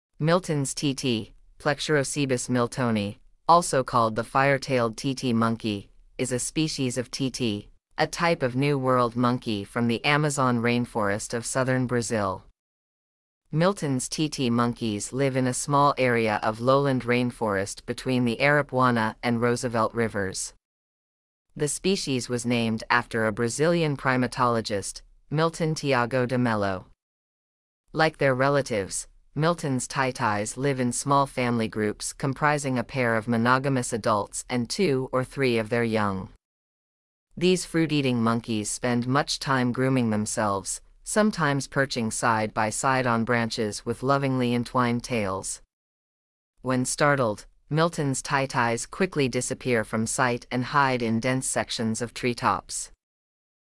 Milton's Titi